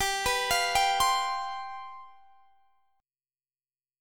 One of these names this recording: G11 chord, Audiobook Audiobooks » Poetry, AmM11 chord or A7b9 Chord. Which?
G11 chord